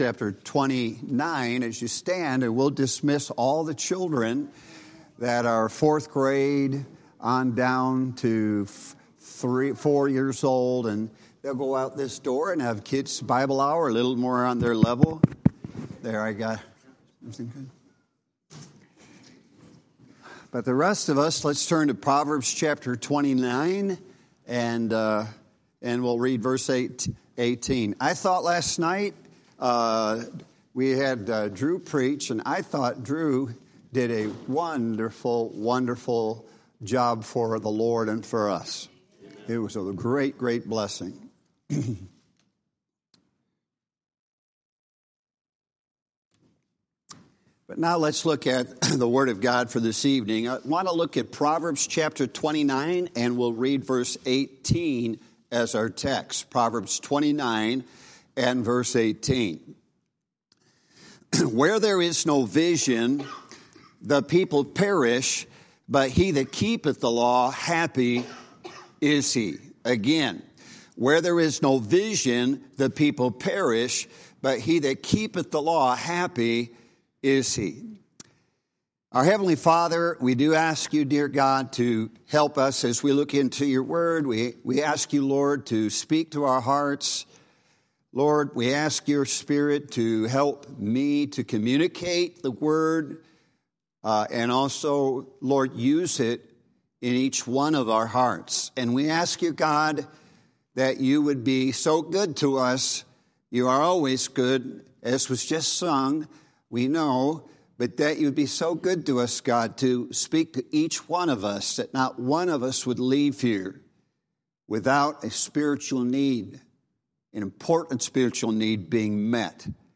Monday of Revival Services 2025